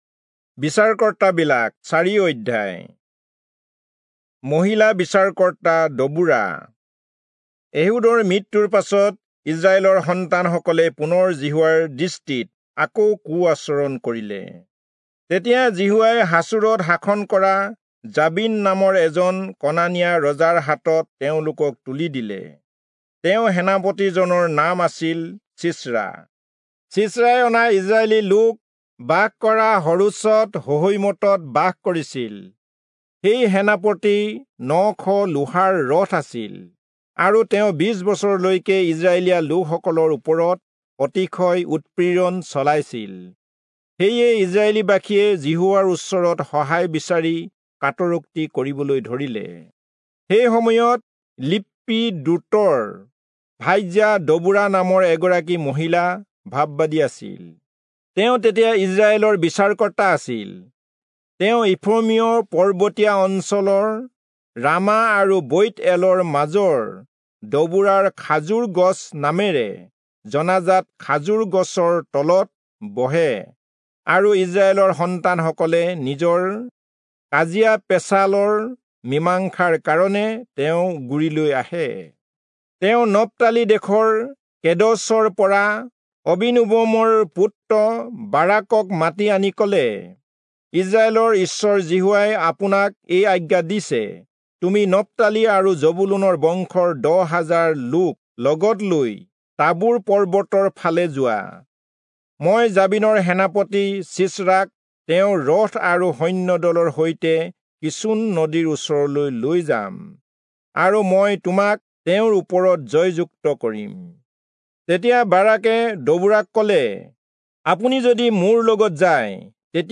Assamese Audio Bible - Judges 10 in Mhb bible version